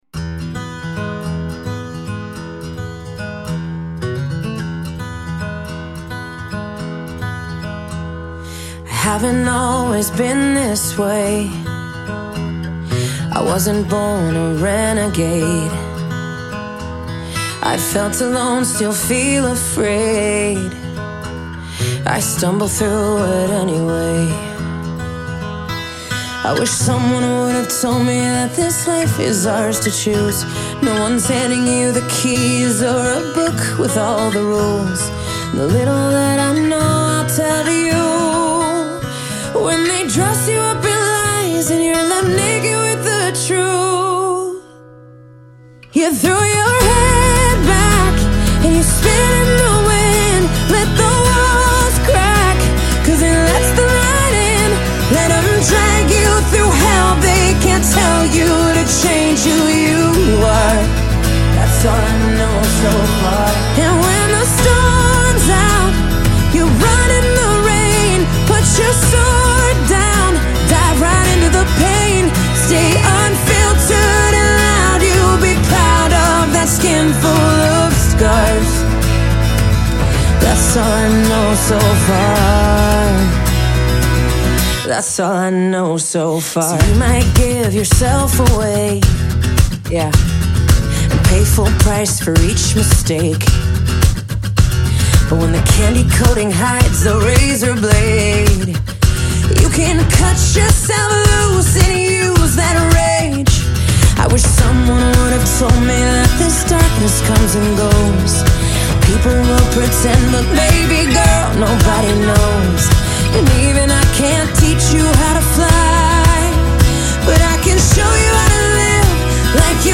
دسته : موسیقی متن فیلم